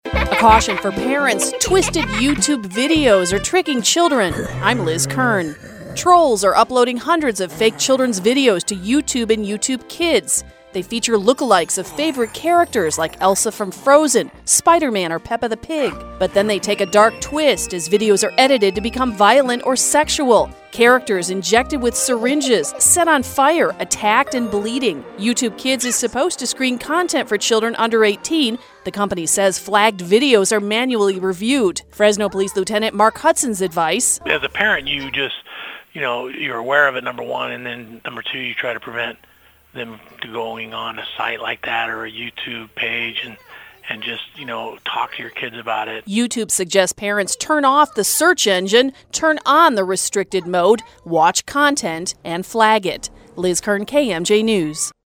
“Twisted” YouTube Videos Are Tricking Children | News Talk 580 and 105.9 KMJ